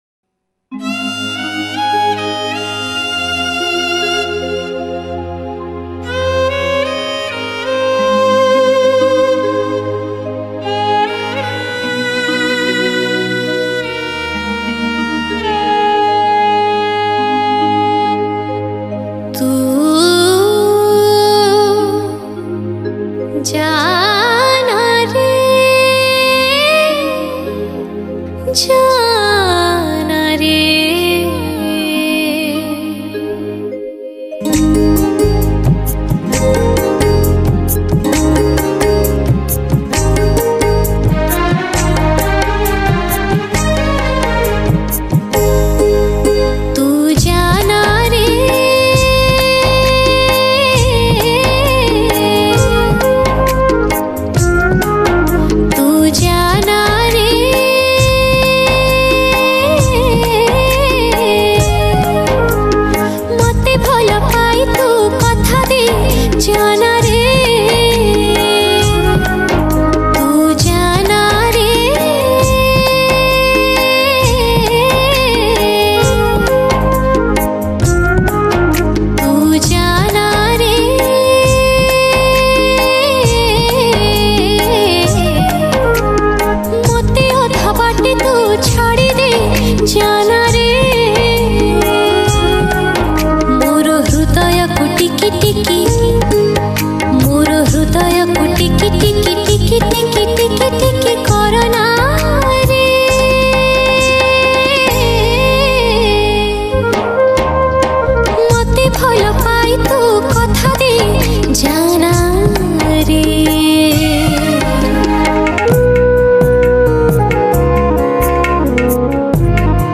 Odia Sad Song